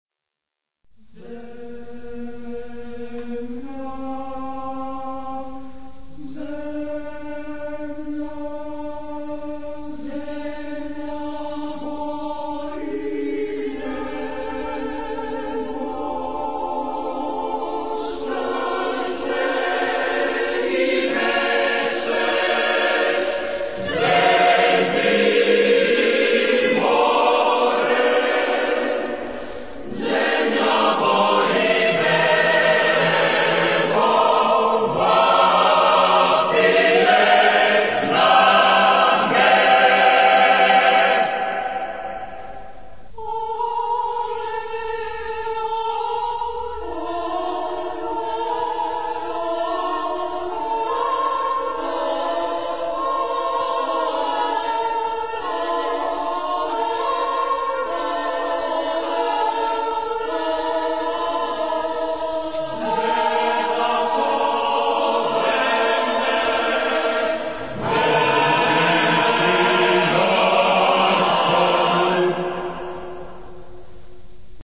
Genre-Stil-Form: weltlich ; zeitgenössisch
Charakter des Stückes: tragisch
Chorgattung: SSAATTB  (7-stimmiger gemischter Chor )
Tonart(en): polytonal
Lokalisierung : 20ème Profane Acappella